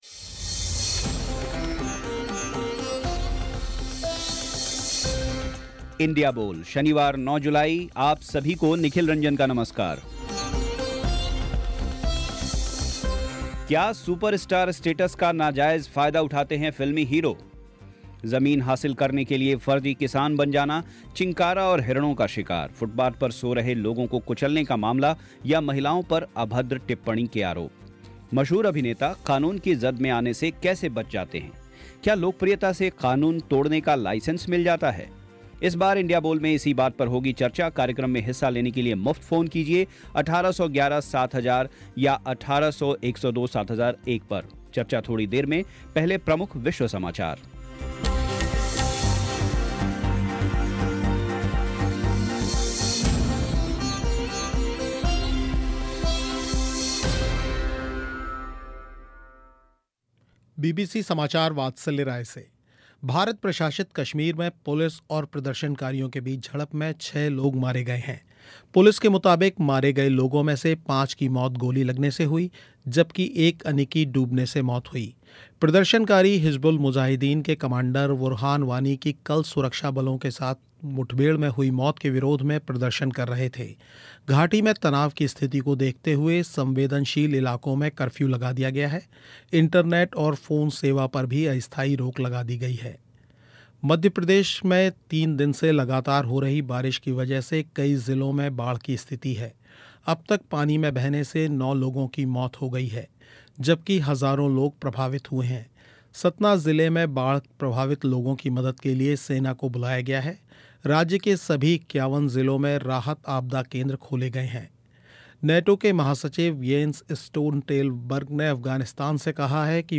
क्या सुपस्टार स्टेटस का नाजायज़ फ़ायदा उठाते हैं फिल्मी हीरो, फर्जी किसान बन कर ज़मीन हासिल करना हो चिंकारा और हिरणों का शिकार, फुटपाथ पर सो रहे लोगों को कुचलने का मामला या महिलाओं पर अभद्र टिप्पणी के आरोप, मशहूर अभिनेता कानून की ज़द में आने से कैसे बच जाते हैं, क्या लोकप्रियता से क़ानून तोड़ने का लाइसेंस मिल जाता है. लोगों की राय सुनिए इंडिया बोल में